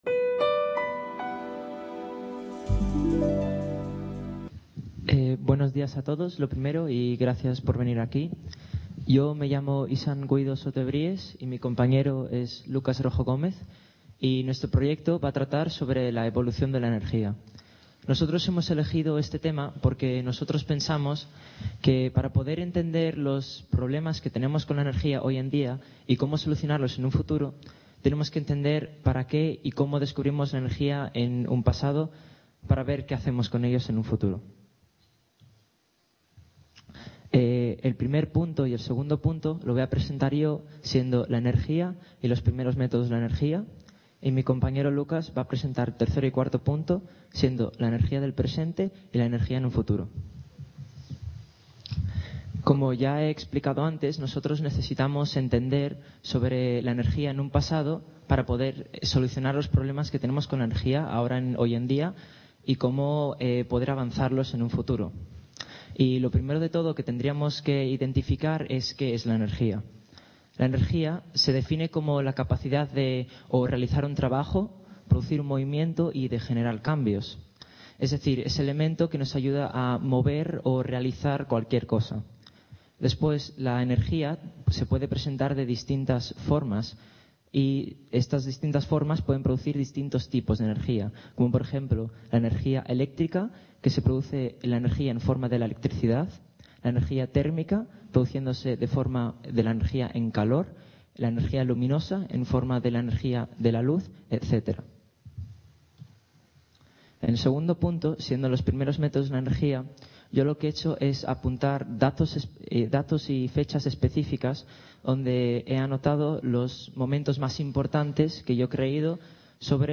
Description La Fábrica de Luz. Museo de la Energía organiza el Congreso de Jóvenes Expertos que, en su primera edición, se centrará en la energía. El evento tendrá lugar en el Aula Magna de la UNED Ponferrada y en el salón de actos de la Universidad de León en el Campus de Ponferrada.